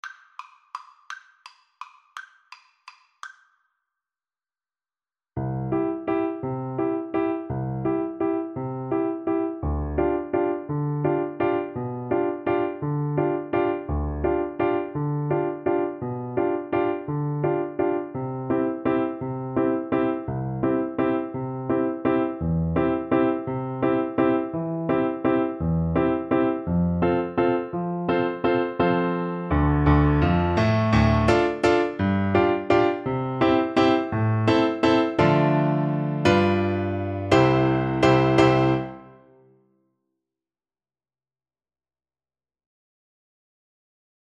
• Unlimited playalong tracks
3/4 (View more 3/4 Music)
=169 Steady one in a bar
C major (Sounding Pitch) (View more C major Music for Violin )